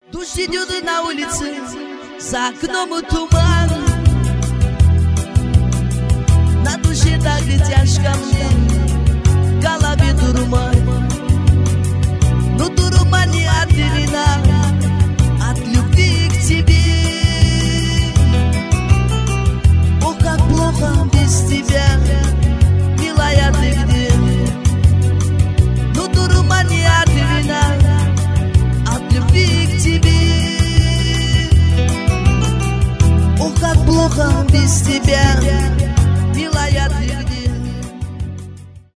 поп , романтические
шансон